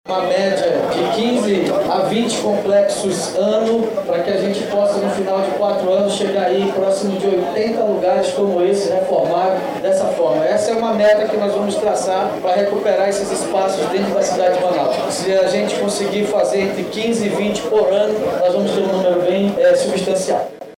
Durante a cerimônia de entrega da obra, o Prefeito de Manaus, Davi Almeida, disse que sua administração tem uma meta de entrega de mais de 15 espaços esportivos reformados por ano, até o fim do seu segundo mandato.